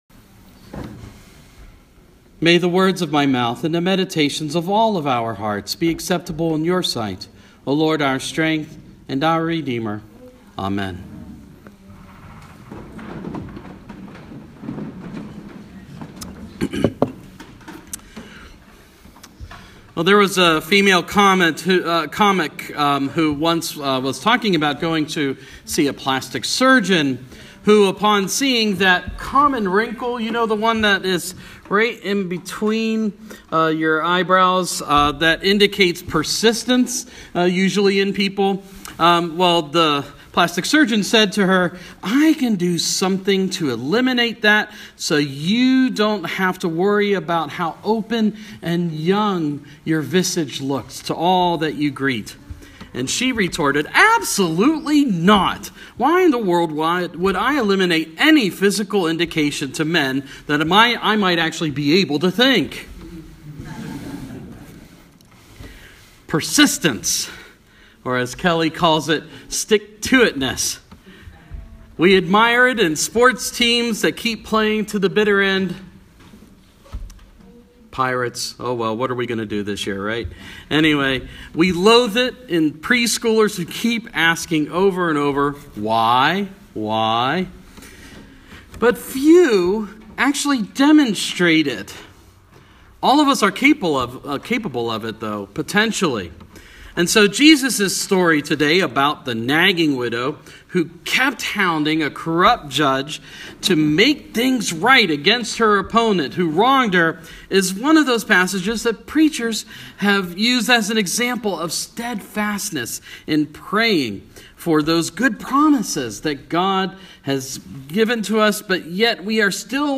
My bishop’s sermon (St Francis):